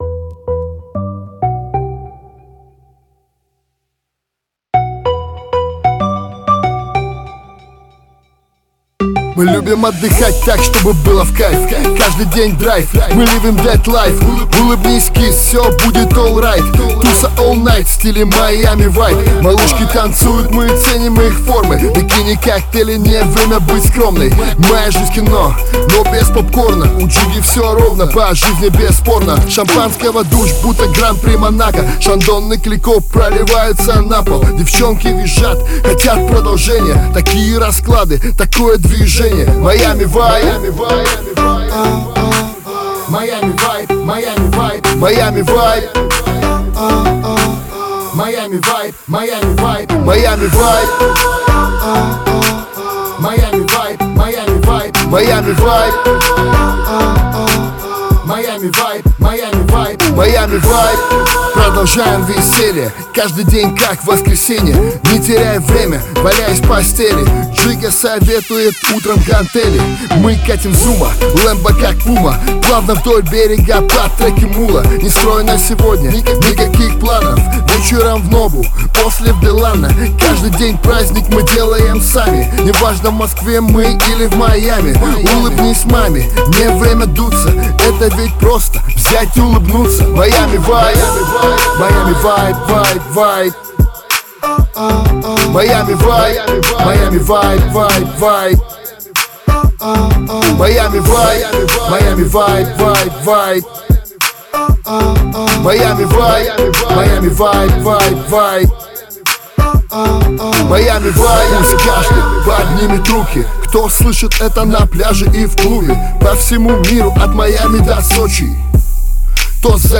Назад в (рэп)...